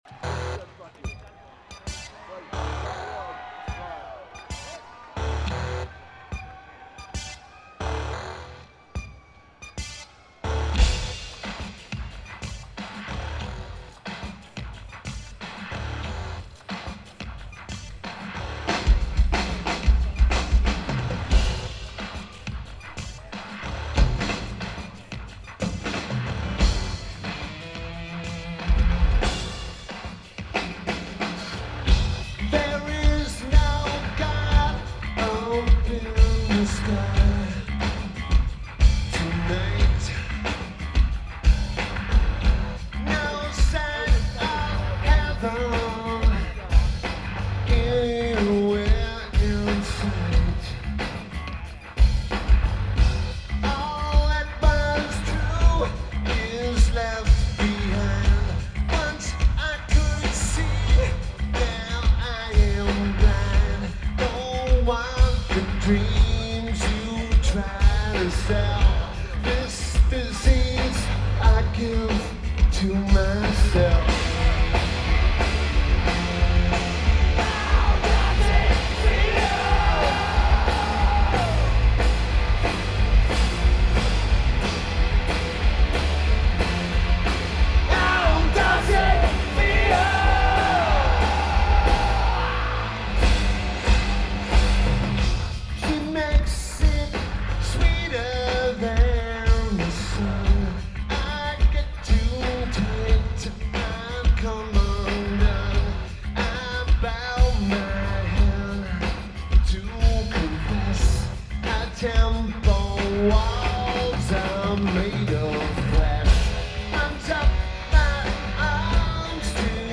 Lineage: Audio - AUD (AT853's (4.7k mod) > Tascam DR-05X)